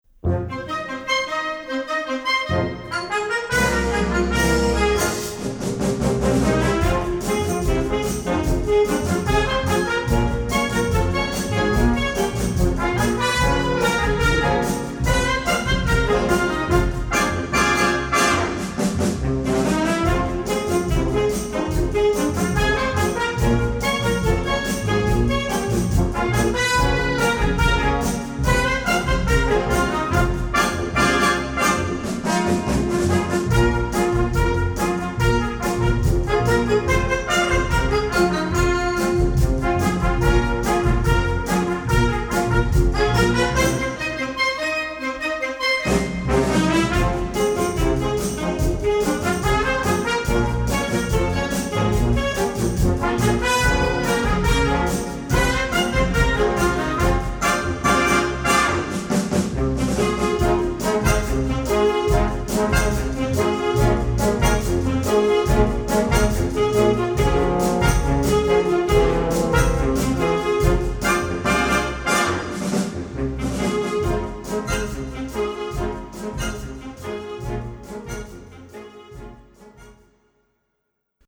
Recueil pour Harmonie/fanfare - Marching Band